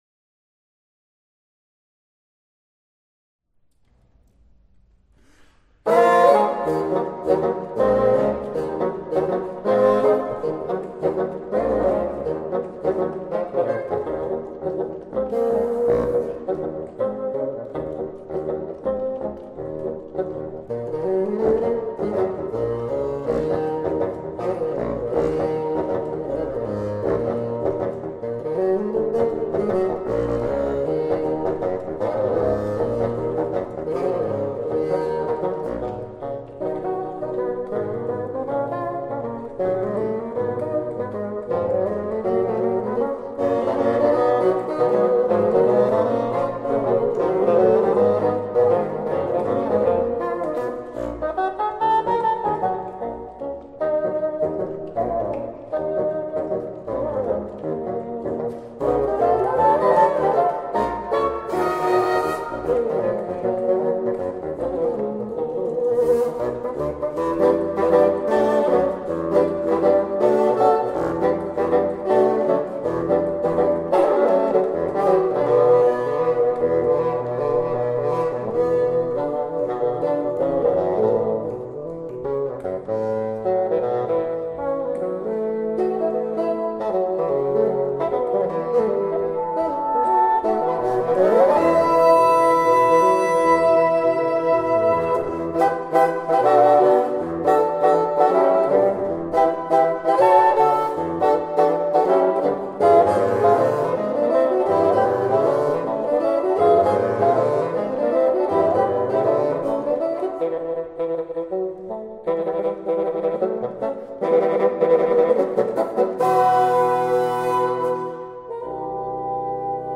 Viento Madera
FAGOT